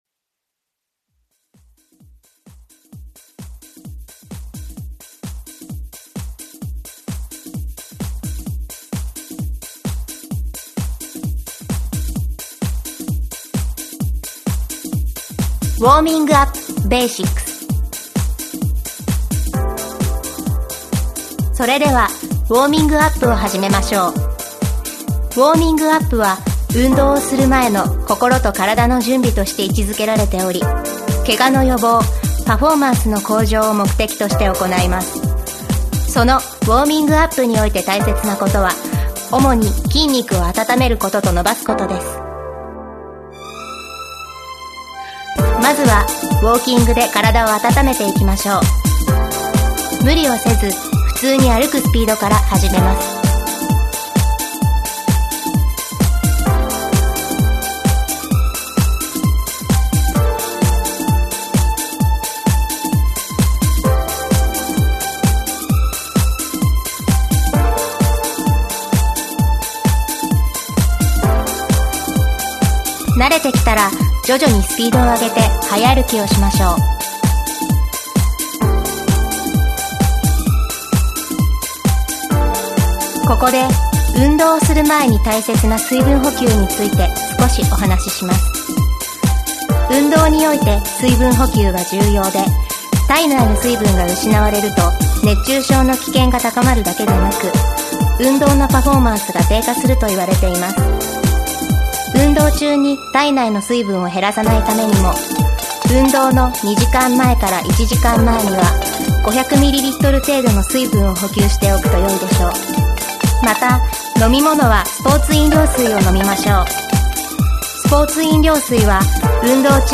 [オーディオブック] Warming Up 【Basics】